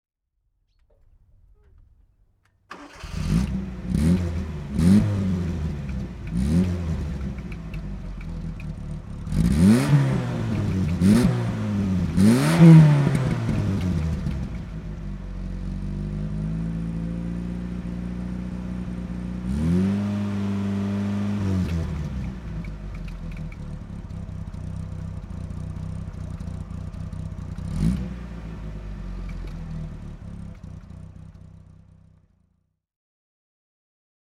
Fiat Ritmo 75 CL (1981) - Starten und Leerlauf